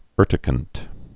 (ûrtĭ-kənt)